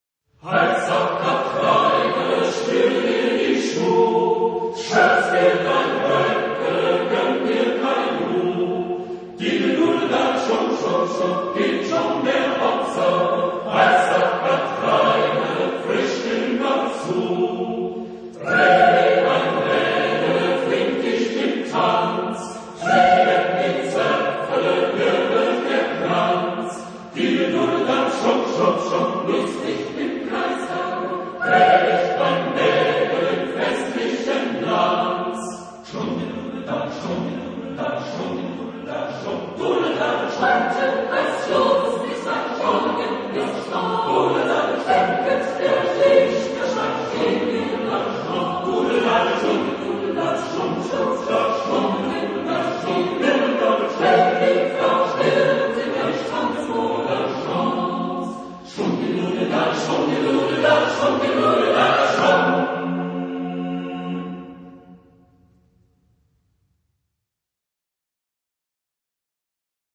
Genre-Style-Form: Folk music ; ballet ; Partsong ; Secular
Type of Choir: SSAATTBB  (8 mixed voices )
Tonality: F major